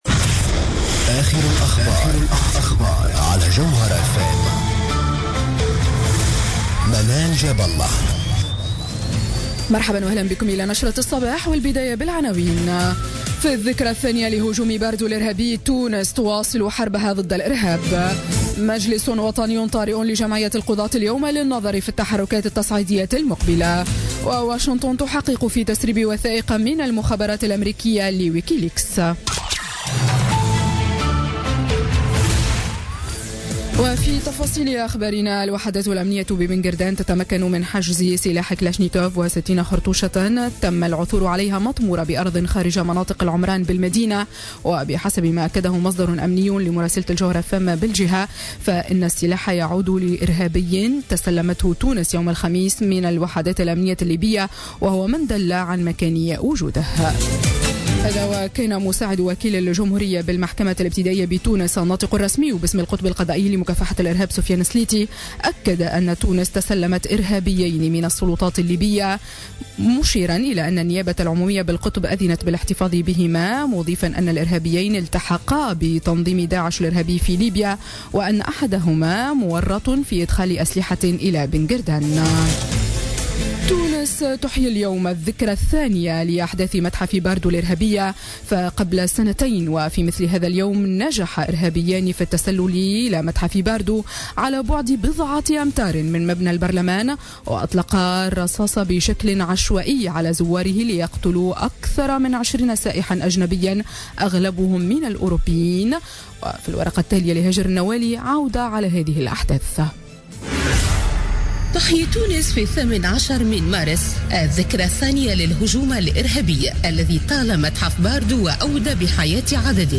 نشرة أخبار السابعة صباحا ليوم السبت 18 مارس 2017